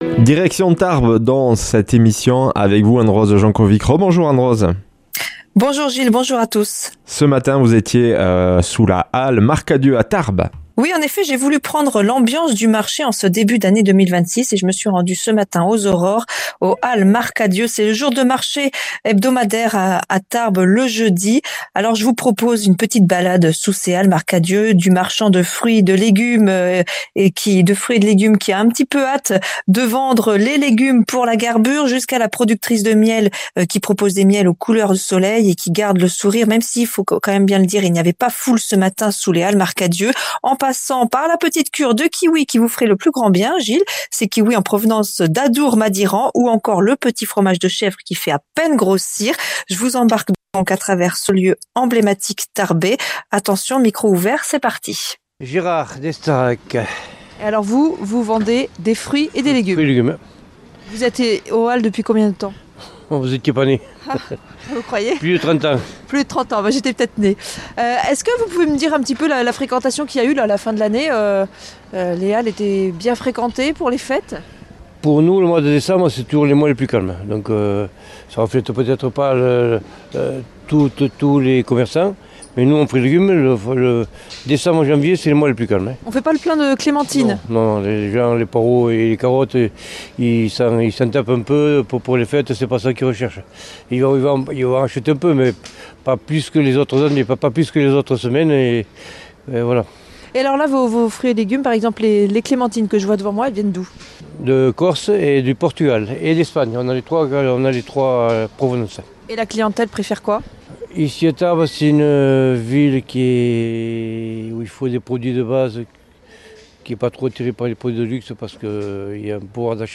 Reportage sous la Halle Marcadieu de Tarbes.